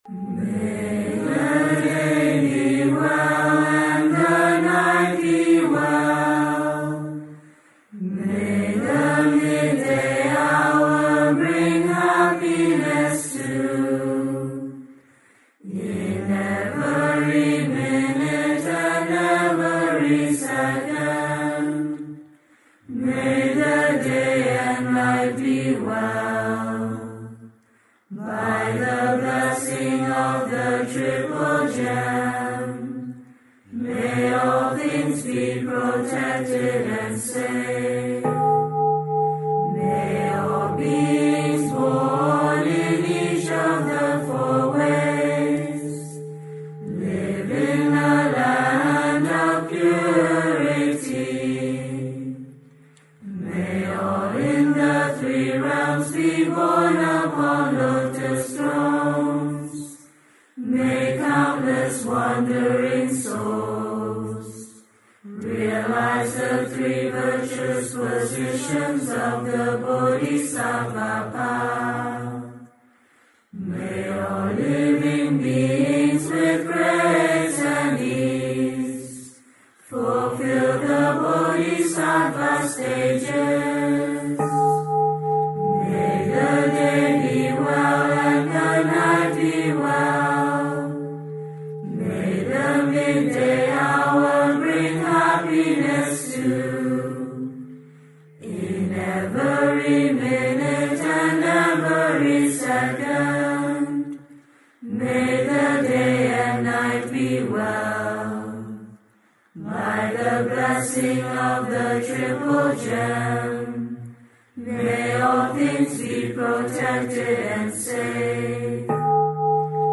梅村僧眾
06-Chanting-May-the-day-be-well.mp3